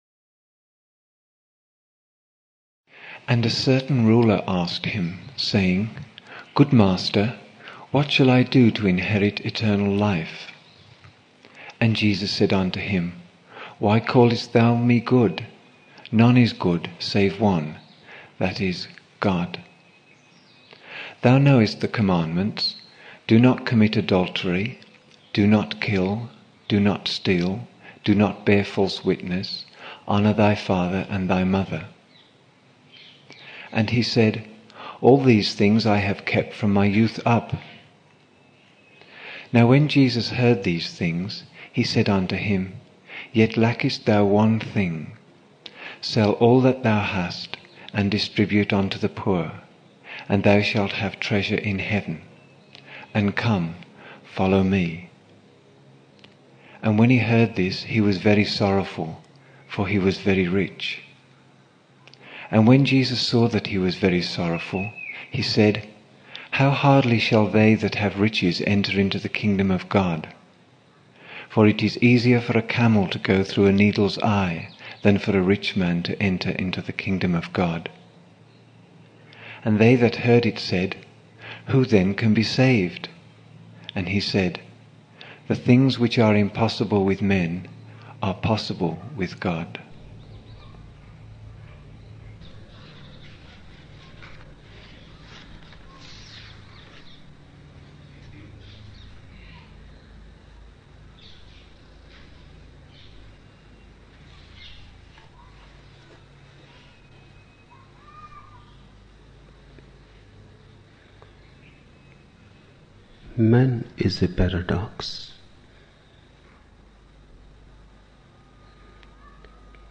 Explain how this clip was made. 10 November 1975 morning in Buddha Hall, Poona, India